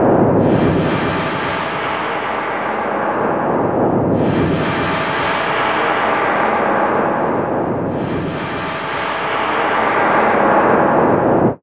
Index of /90_sSampleCDs/Best Service ProSamples vol.36 - Chillout [AIFF, EXS24, HALion, WAV] 1CD/PS-36 WAV Chillout/WAV Synth Atmos 4